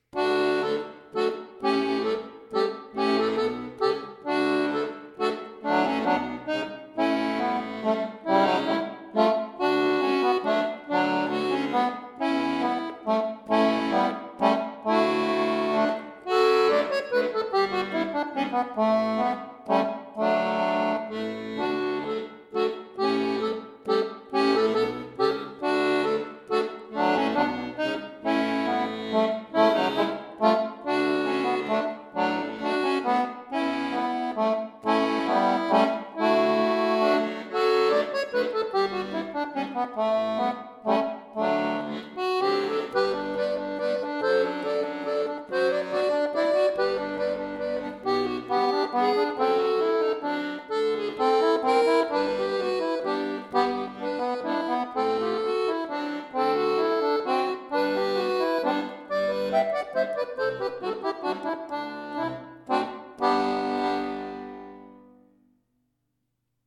Altbekanntes Volkslied, neu arrangiert für Akkordeon solo